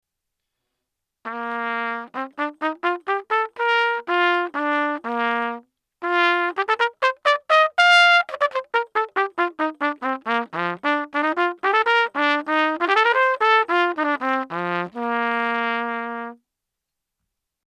JHS Colour Box Clip 2 - Trumpet